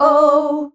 OUUH  D.wav